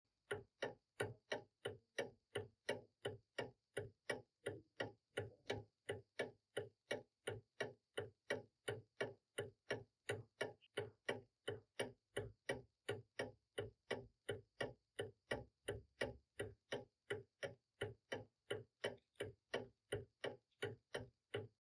Звук тиканья часов
21. Звук громкого тиканья старинных настенных часов с маятником
tik-chasov-star.mp3